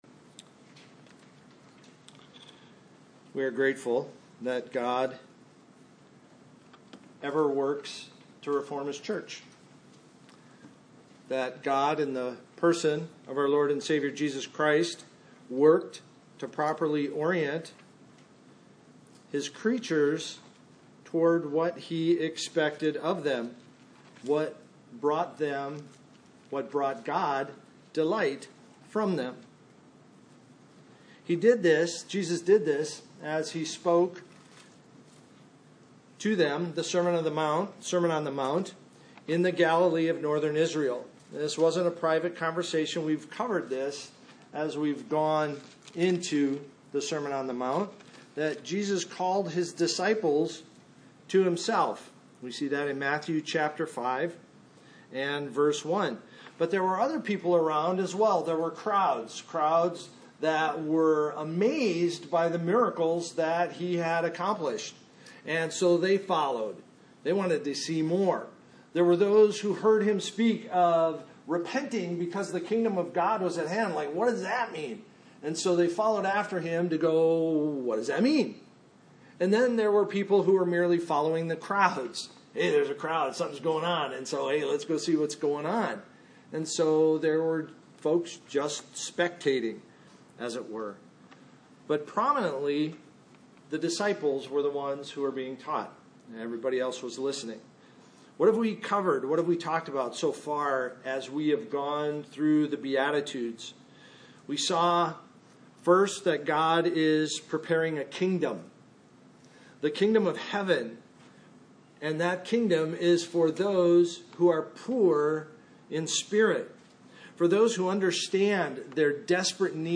2025 The Mighty Meek Preacher